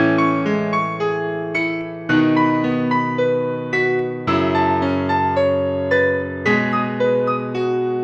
Category: Cinematic Ringtones